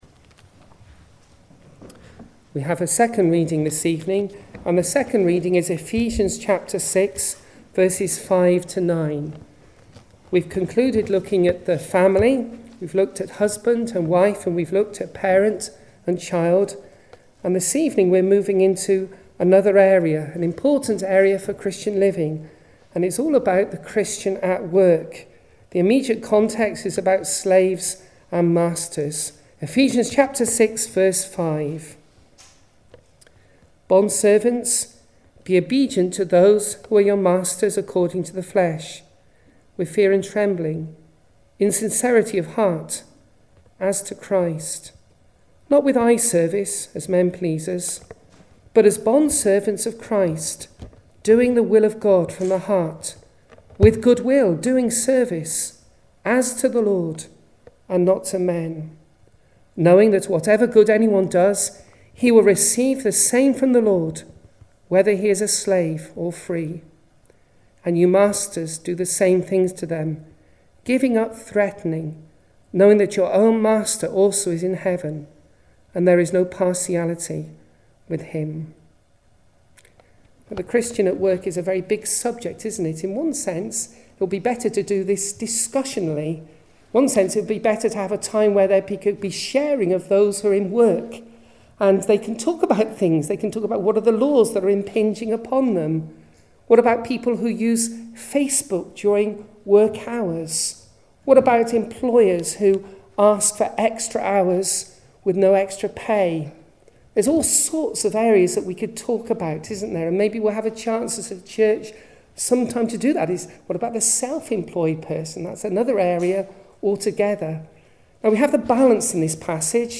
Series: Ephesians Passage: Ephesians 6:5-9, 1 Peter 2:13-25 Service Type: Sunday Evening Bible Reading (until 1:20)